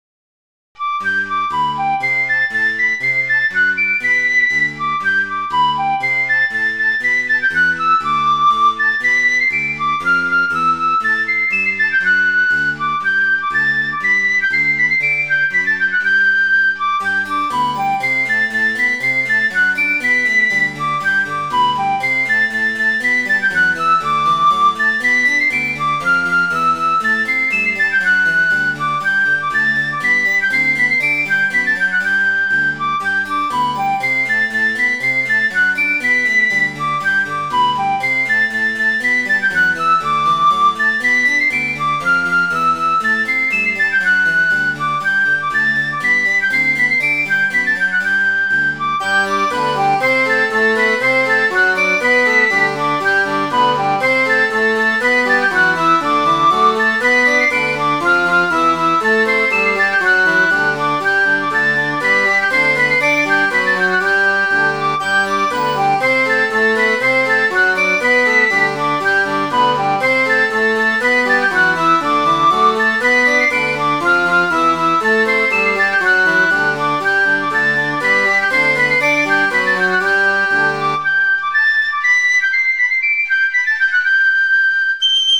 Midi File, Lyrics and Information to Can of Grog
canofgrog.mid.ogg